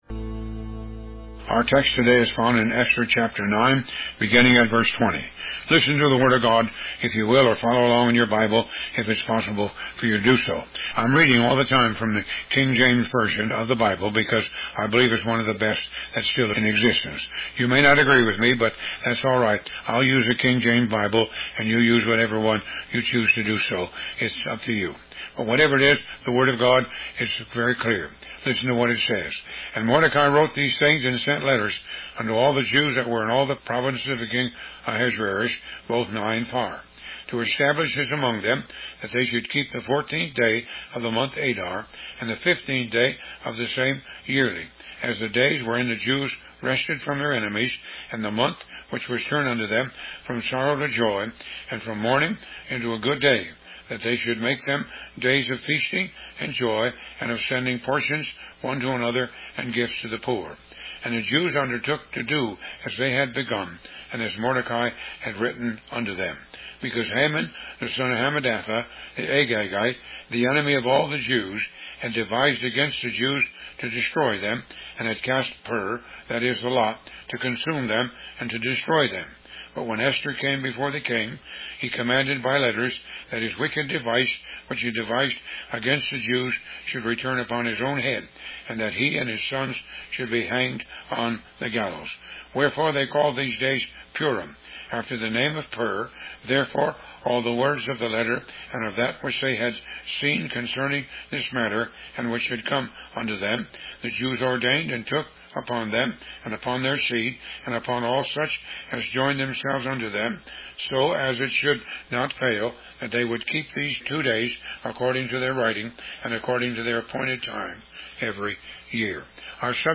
Bible Study
Talk Show